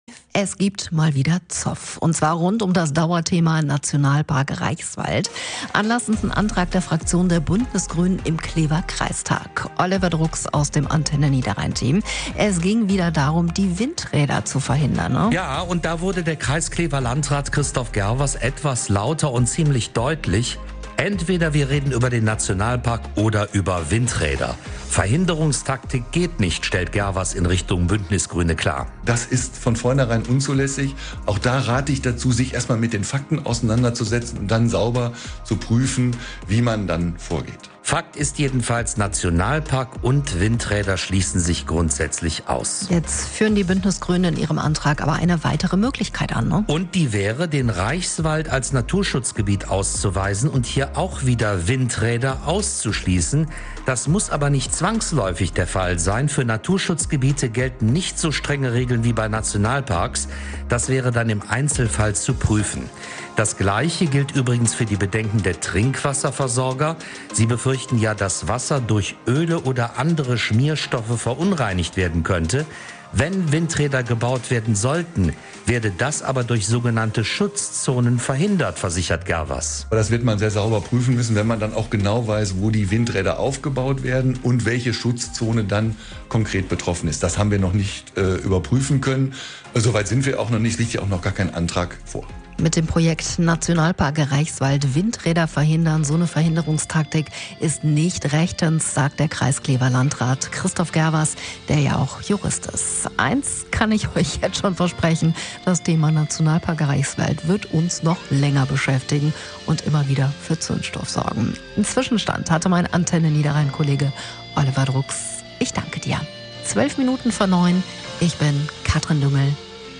Anlass ist ein Antrag der Fraktion der Bündnisgrünen im Klever Kreistag. Der Kreis Klever Landrat und Jurist Christoph Gerwers stellt im Antenne Niederrhein Interview klar, was rechtlich möglich ist und was nicht.